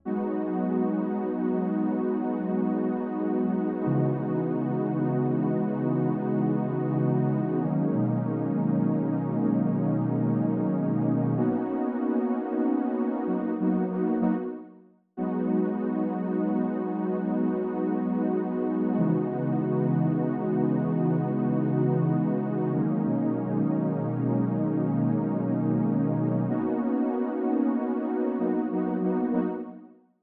14 pad A2.wav